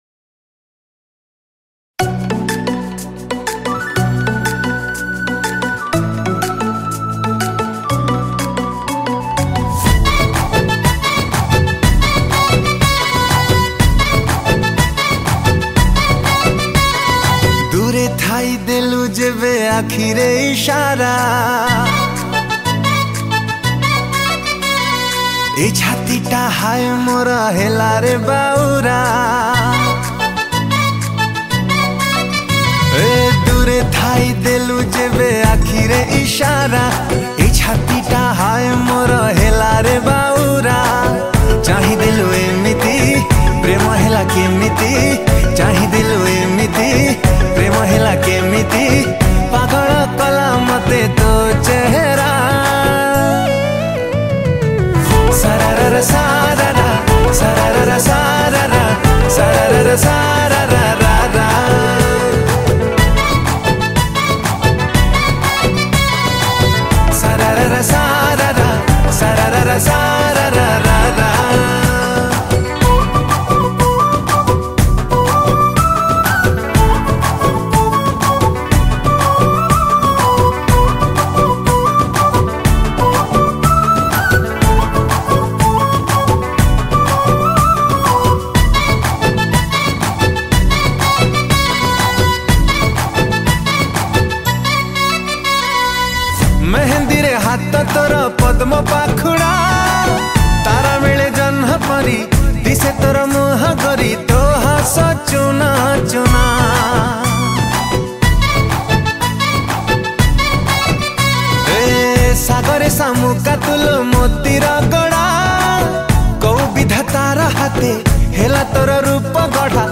Keyboard Programing
Drums
Recorded At : Milan Studio, CTC